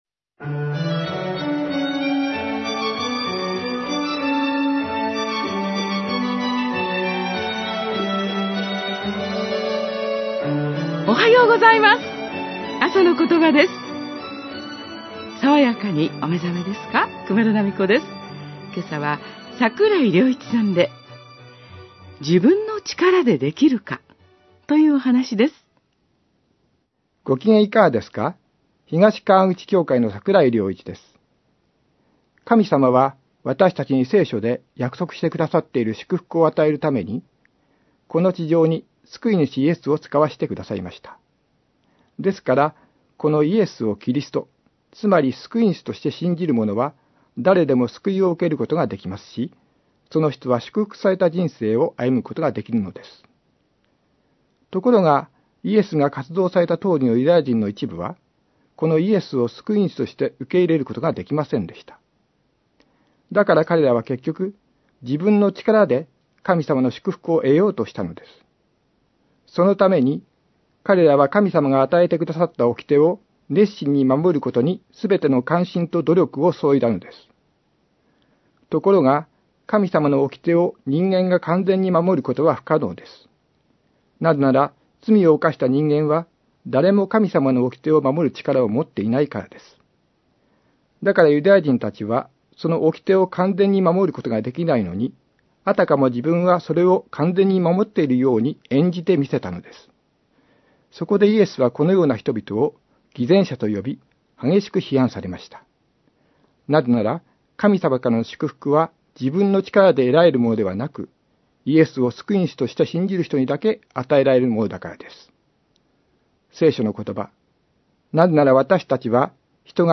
メッセージ： 自分の力でできるか